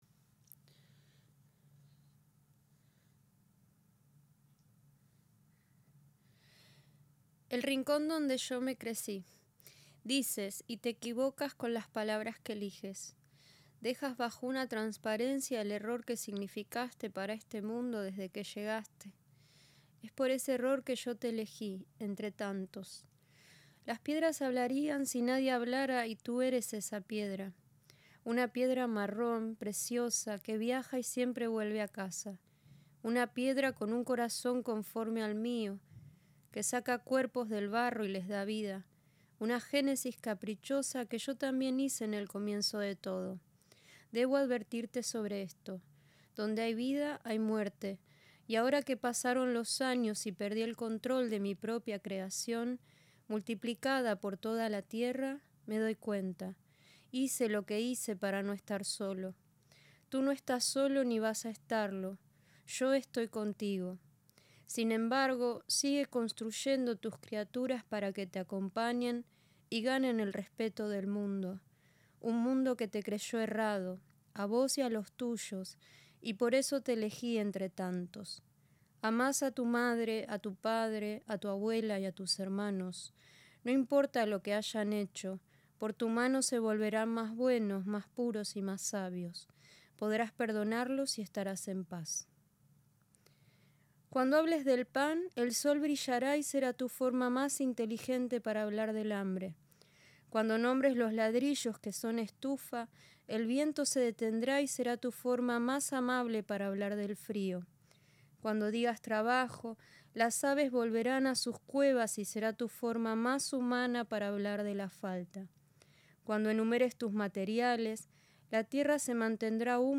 Los textos tienen como objetivo ampliar el mundo de referencias y significados de las obras, a través de la voz particularísima de los autores invitados. De este modo, sin pretender que describan lo que vemos, buscan hacerse eco de los temas, inquietudes o historias que nutrieron a los artistas.